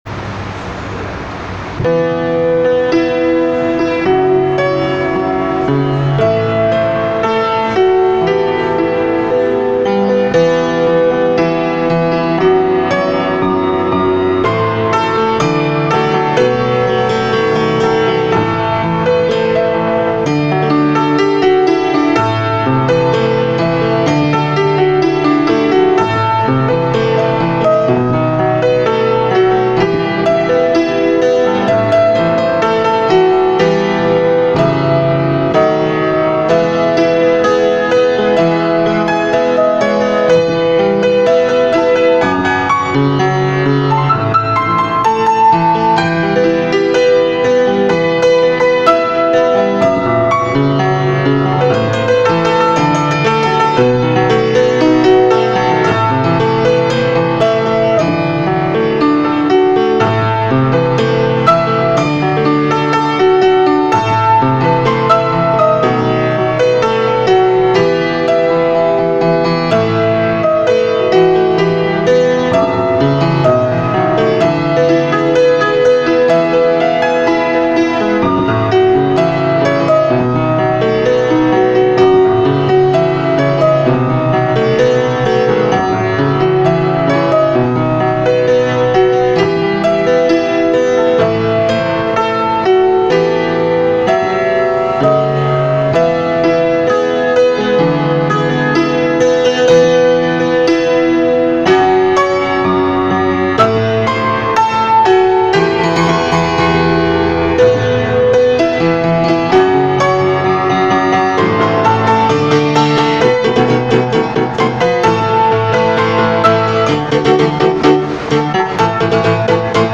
ההקלטה טעונה שיפור.
ניכר שמדובר במנגינה להקשבה ולא בפלייבק למשל.